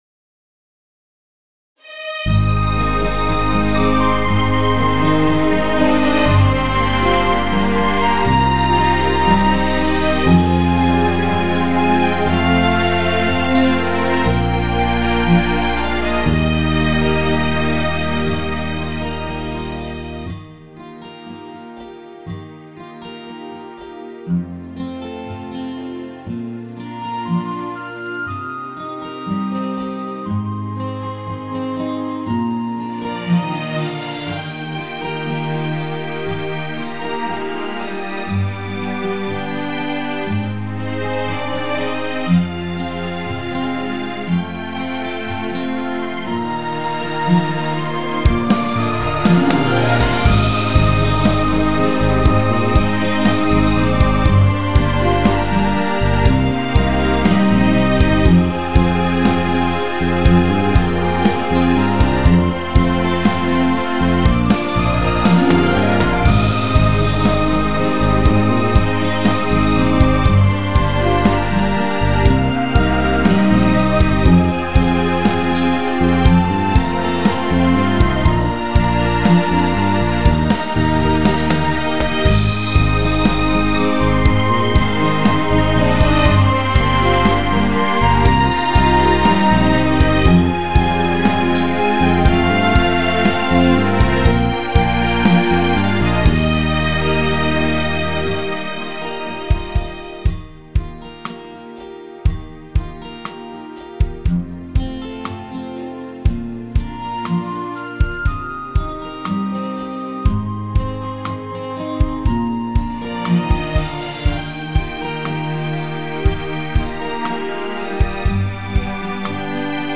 yl23411永利官网院歌《化药之歌》伴奏